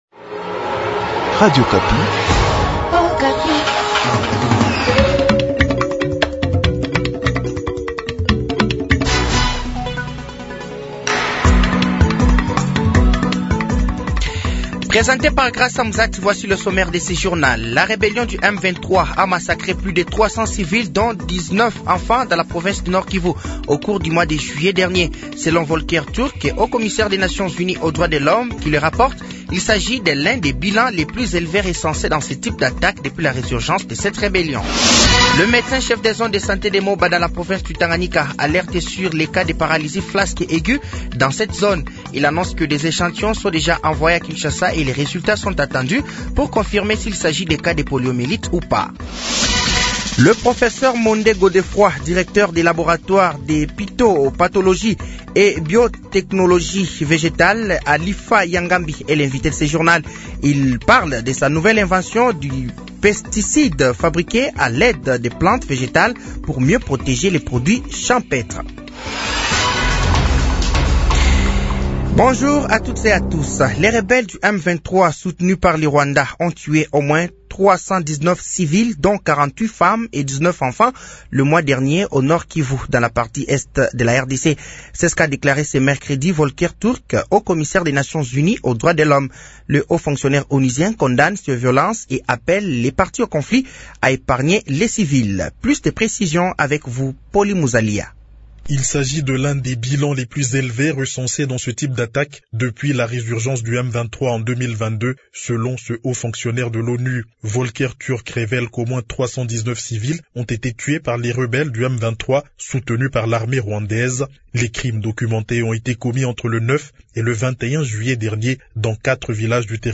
Journal midi
Journal français de 12h de ce jeudi 07 août 2025